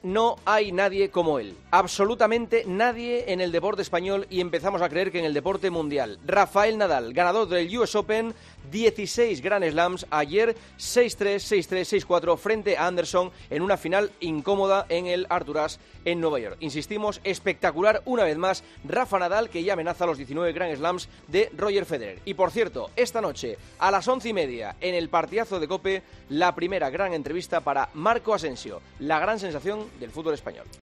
El comentario de Juanma Castaño
La victoria de Nadal consiguiendo su tercer US Open y su 16º Grand Slam, en el comentario de Juanma Castaño en 'Herrera en COPE'.